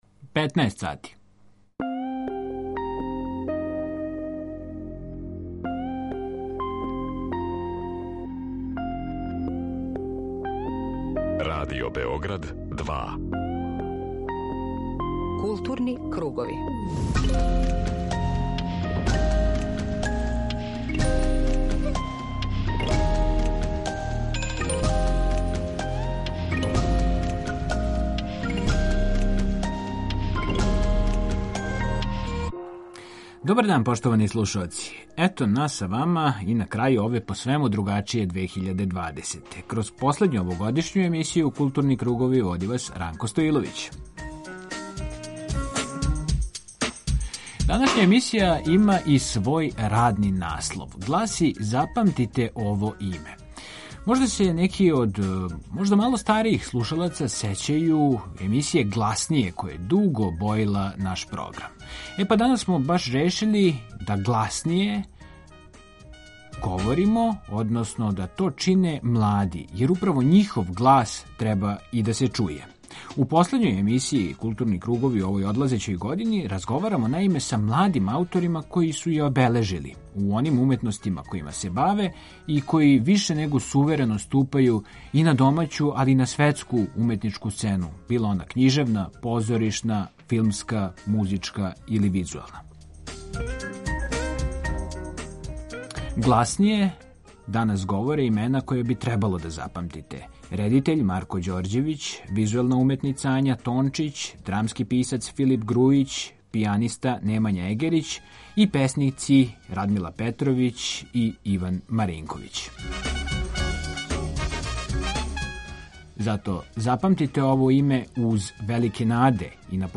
У последњој емисији Културни кругови у одлазећој години разговарамо са младим ауторима који су је обележили у уметностима којима се баве и који суверено ступају на домаћу (и светску!) књижевну, позоришну, филмску, музичку и сцену визуелних уметности.